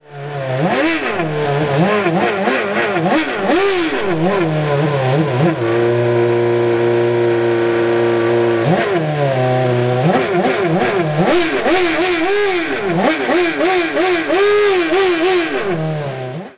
Lyt til RedBull Formel 1 raceren, da Renault motoren blev startet op foran Københavns Rådhus >>>
RedBull Renault paa Raadhuspladsen.mp3